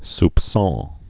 (sp-sôɴ, spsŏn)